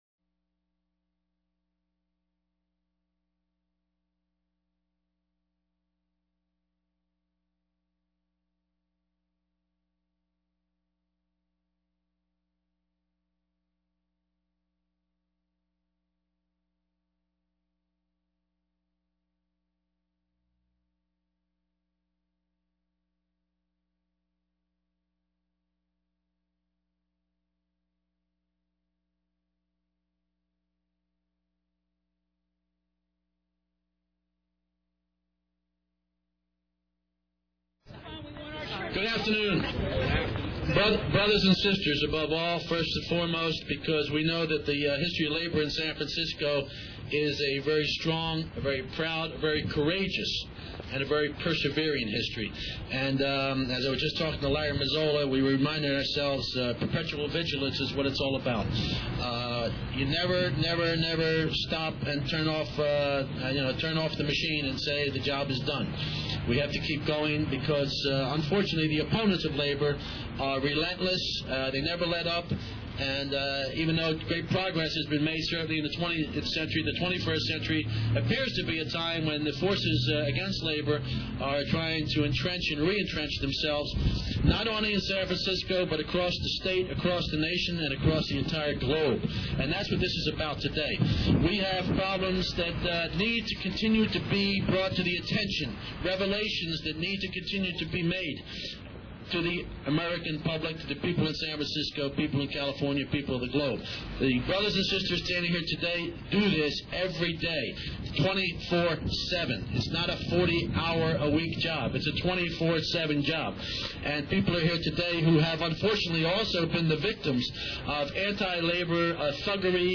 On Monday Dember 5, 2005 a SF City hearing was held on agressive union busting in San Francisco and Northern California.
Union activists testified regarding abuses ranging from illegal firings and harassment to physical violence and threats thereof, making evident wide-ranging and systematic aggressive efforts to squelch basic human rights.